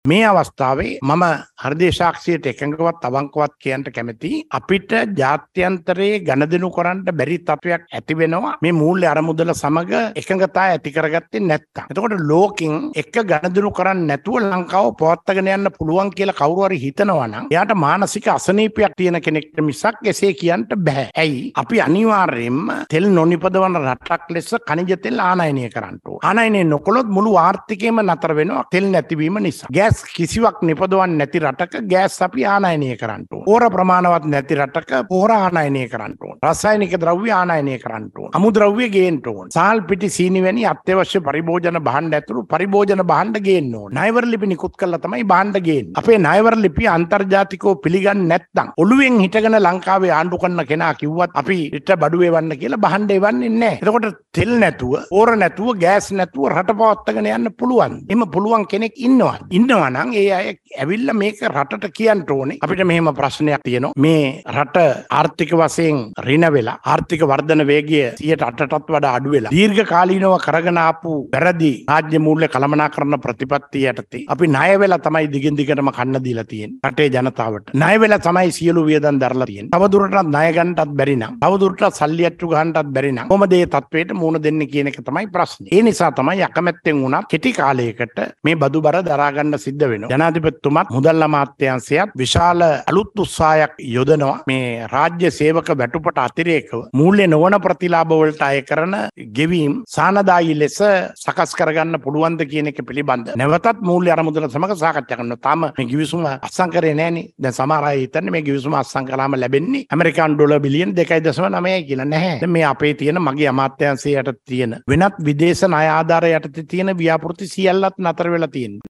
මේ අතර ජාත්‍යන්තර මූල්‍ය අරමුදල සමඟ ශ්‍රී ලංකාව සිදු කරන ගනුදෙනු සම්බන්ධයෙන් අද පැවති කැබිනට් තීරණ දැනුම් දීමේ මාධ්‍ය හමුවේදී අමාත්‍ය බන්ධුල ගුණවර්ධන මහතා අදහස් පළ කළා.